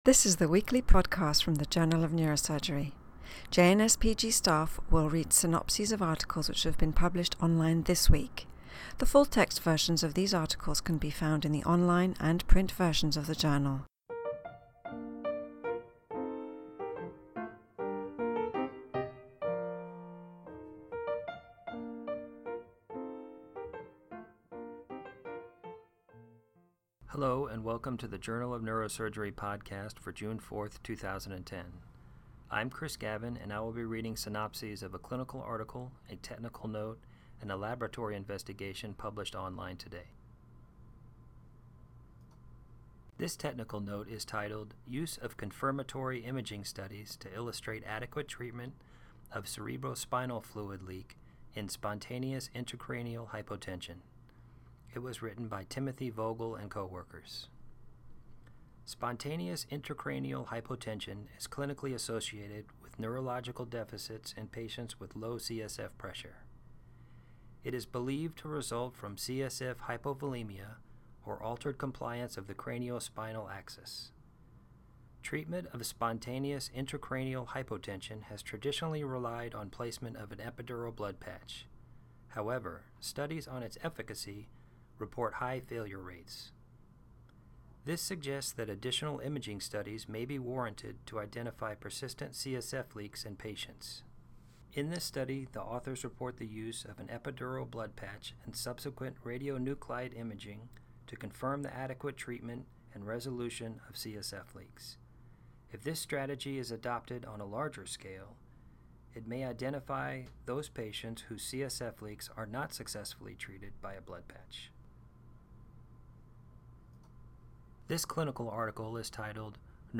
reads synopses of Journal of Neurosurgery articles published online on June 4, 2010.